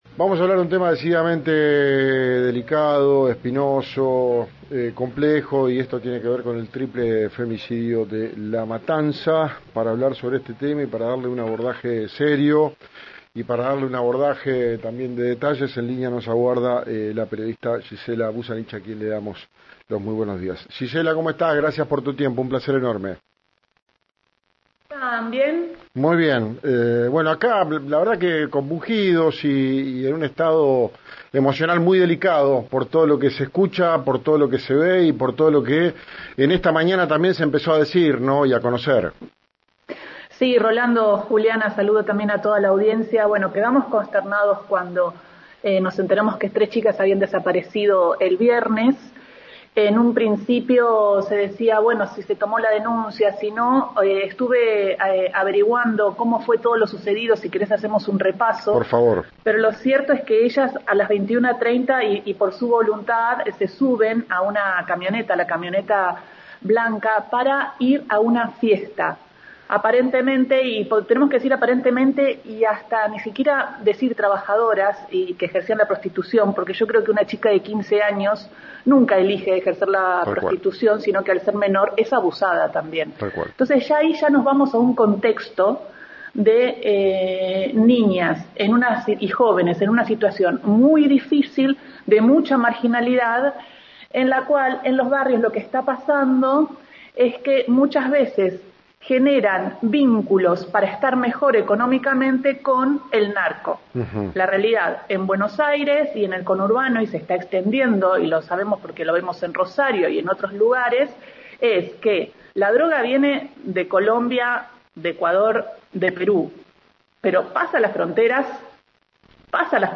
informe especial